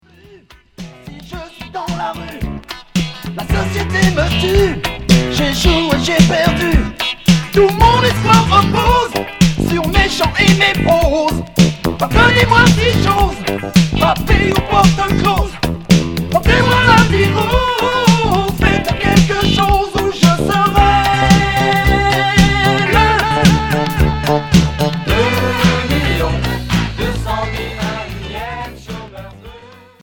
Pop rock Unique 45t retour à l'accueil